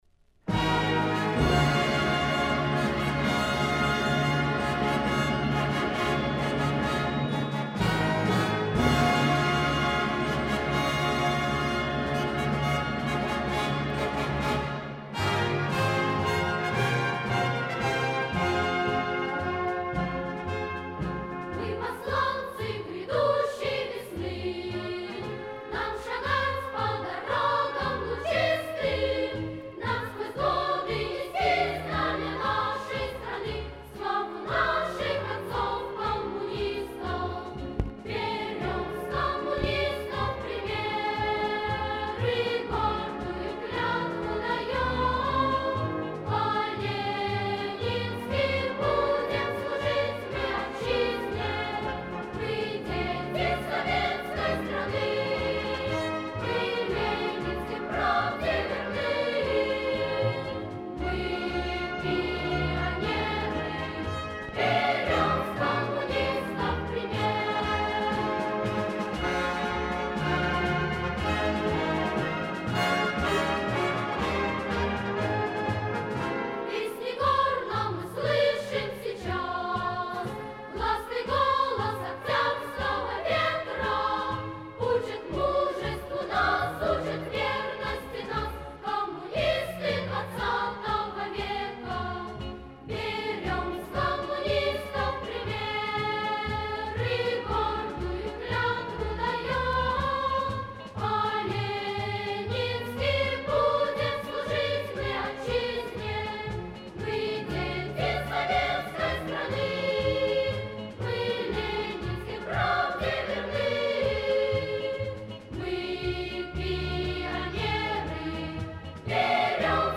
Пионерская песня о верности Ленинским идеям